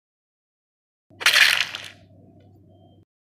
Play, download and share among us kill quand on est imposteur original sound button!!!!